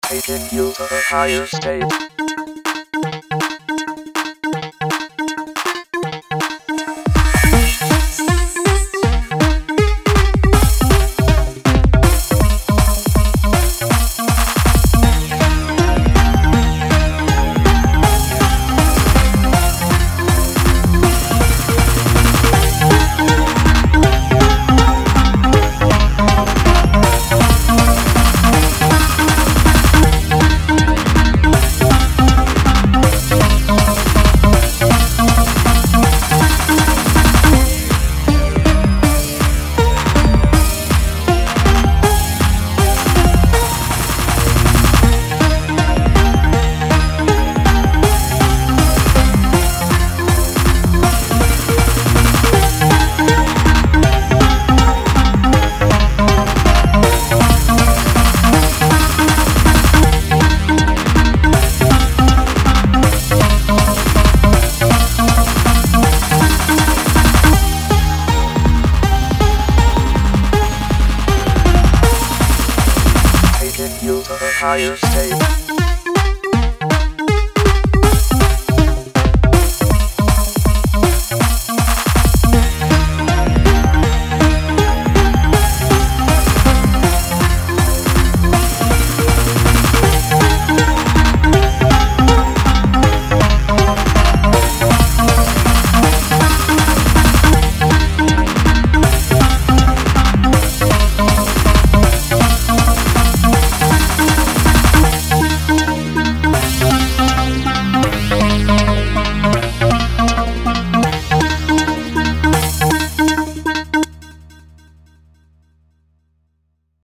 Robot voice